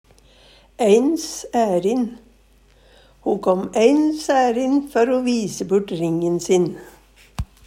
eins ærin - Numedalsmål (en-US)